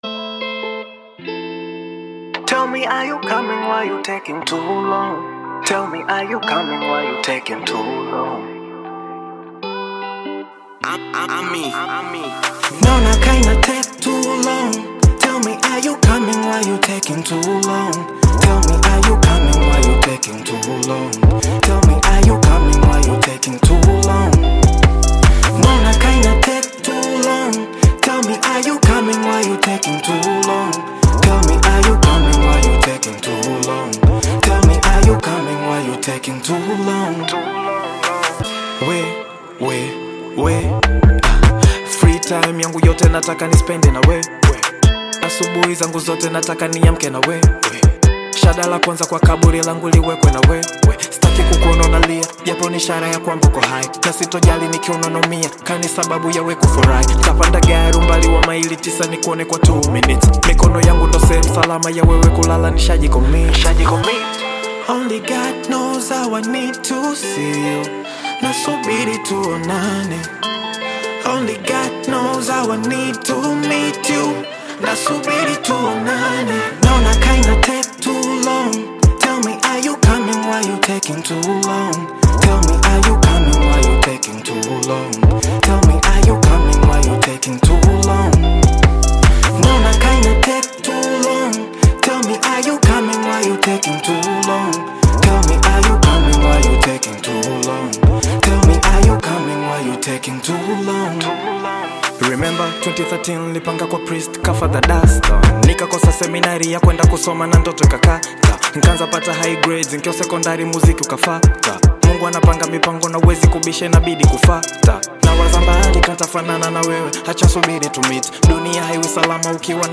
Bongo Flava music track
Tanzanian Bongo Flava artist, singer, and songwriter
This catchy new song